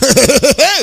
laugh2